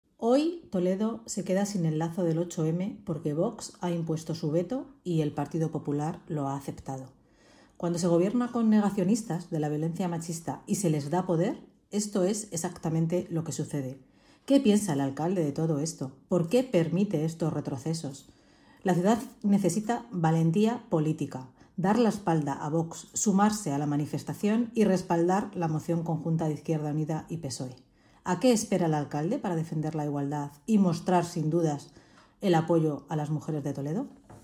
AUDIO-PORTAVOZ-PSOE-sobre-colocacion-lazo-8M-en-Toledo.mp3